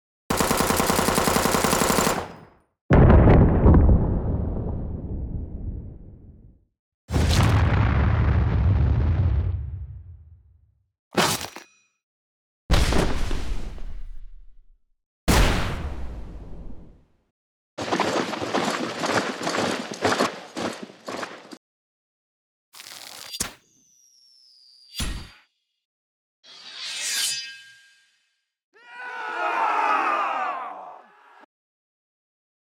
All samples were recorded at 96kHz 24 bit with a Zoom H5 and Sennheiser ME 67.
EXPLDsgn_Explosion Far 01_MASFX_NONE.wav
WEAPSwrd_Sword Single Clash 01_MASFX_NONE.wav